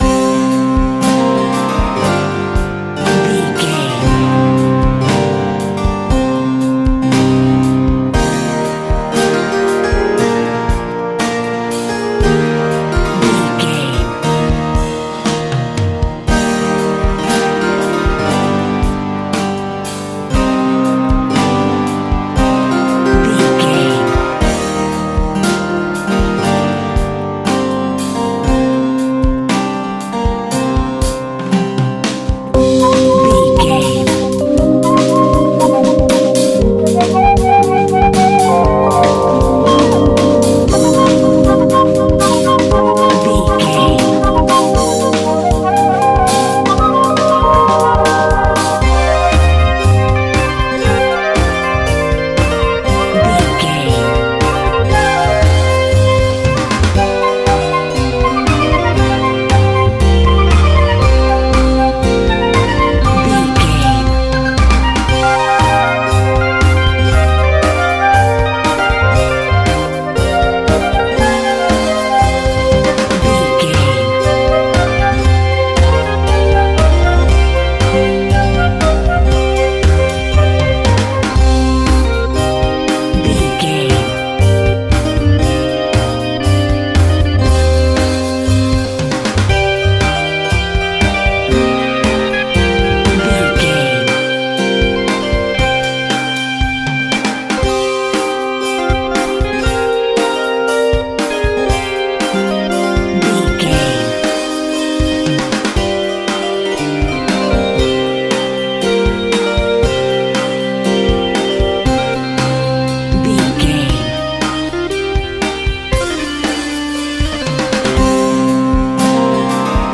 Aeolian/Minor
hard
groovy
powerful
electric guitar
bass guitar
drums
organ